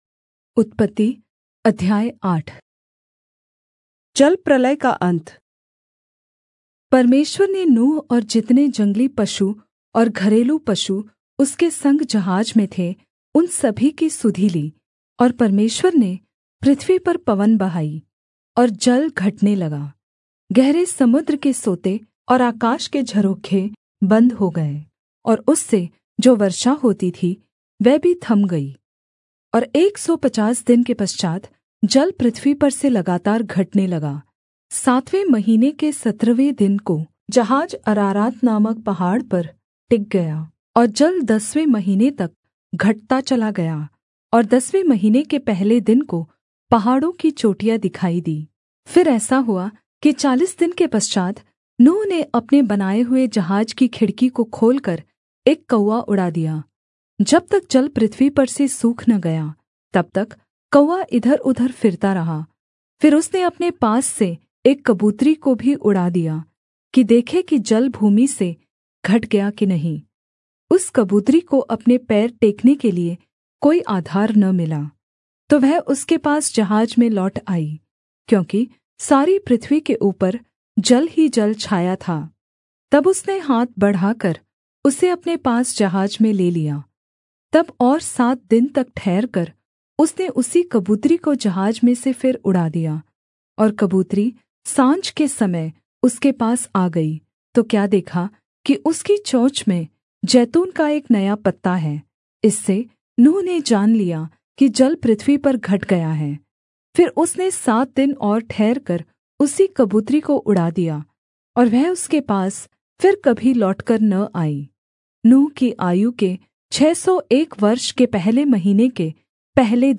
Hindi Audio Bible - Genesis 48 in Irvhi bible version